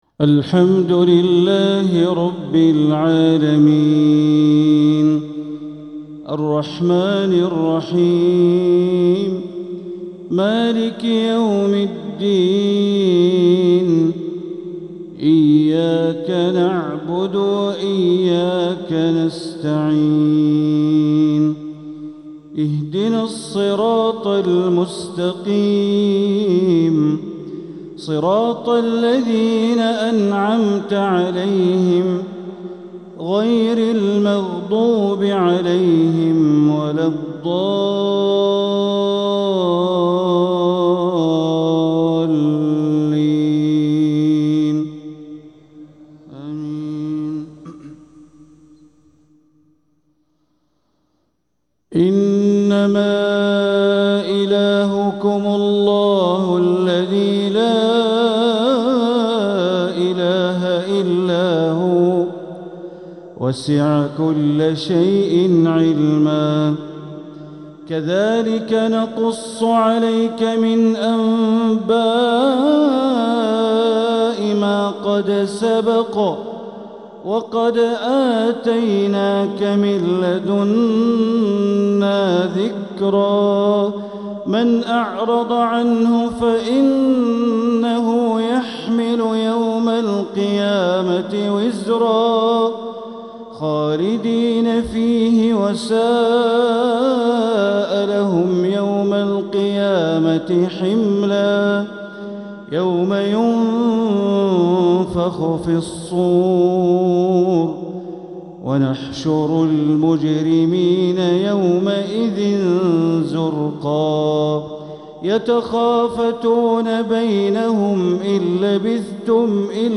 تلاوة من سورة طه عشاء الثلاثاء ٢٧محرم ١٤٤٧ > 1447هـ > الفروض - تلاوات بندر بليلة